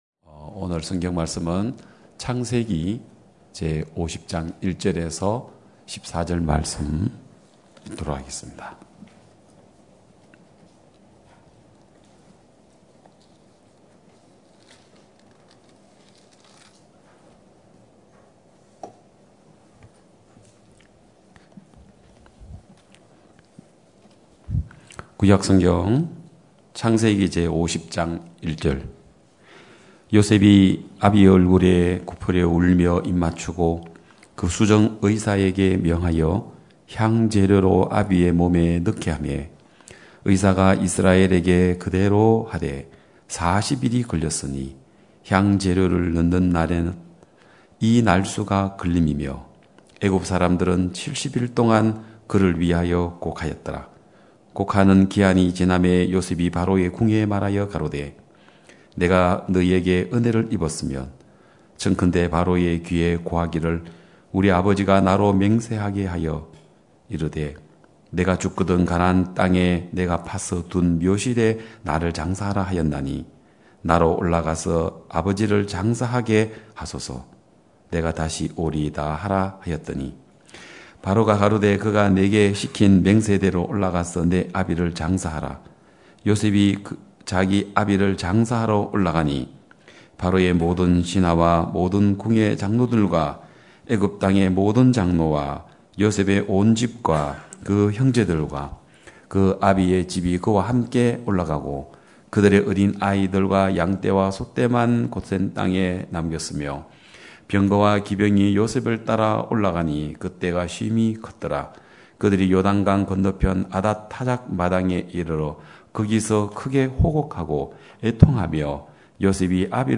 2021년 8월 29일 기쁜소식양천교회 주일오전예배
성도들이 모두 교회에 모여 말씀을 듣는 주일 예배의 설교는, 한 주간 우리 마음을 채웠던 생각을 내려두고 하나님의 말씀으로 가득 채우는 시간입니다.